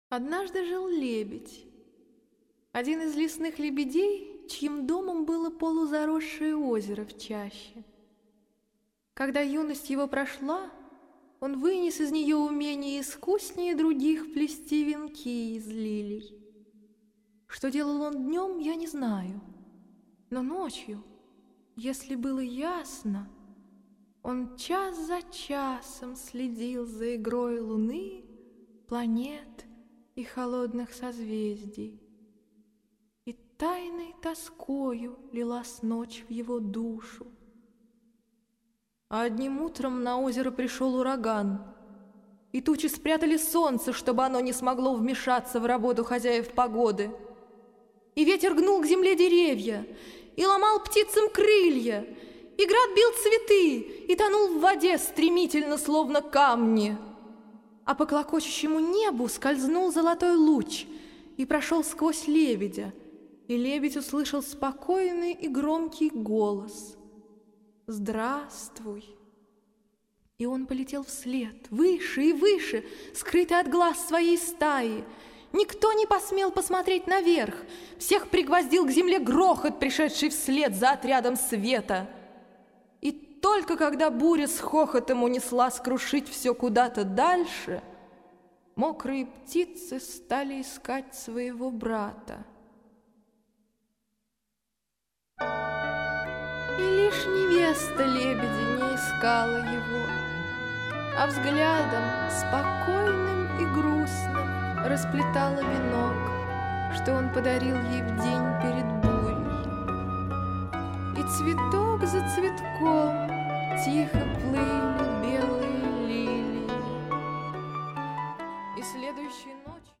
"неоклассика" - от академизма до прогрессивного фолка.